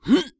CK格挡1.wav
CK格挡1.wav 0:00.00 0:00.40 CK格挡1.wav WAV · 34 KB · 單聲道 (1ch) 下载文件 本站所有音效均采用 CC0 授权 ，可免费用于商业与个人项目，无需署名。
人声采集素材/男2刺客型/CK格挡1.wav